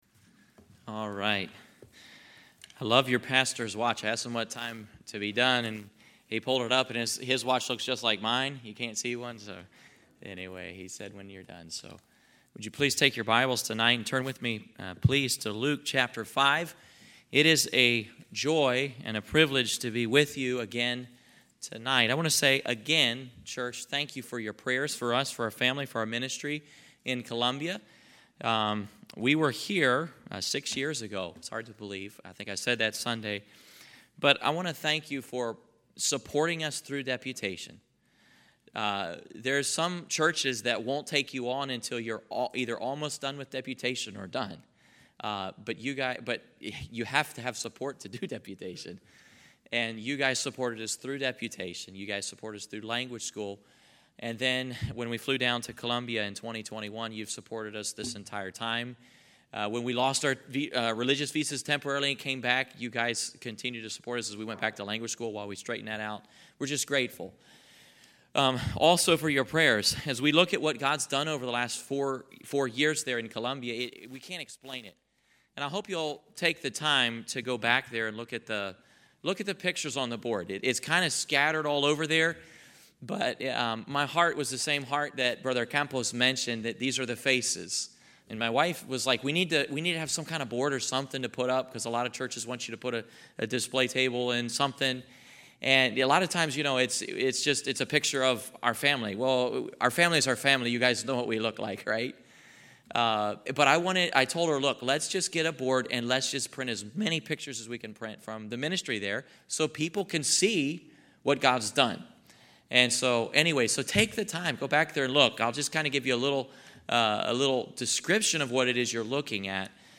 2025 Missions Conference , Sermons